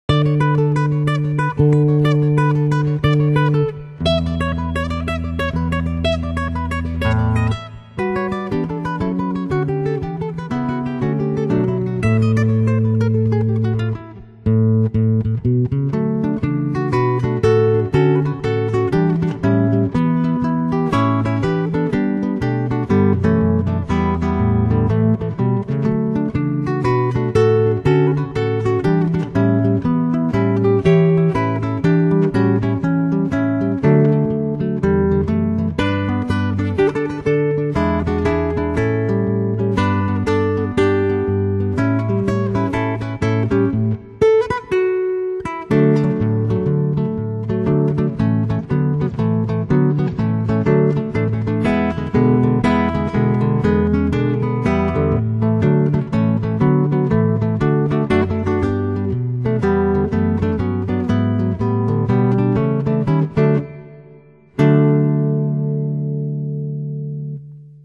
爵士版？这个要听听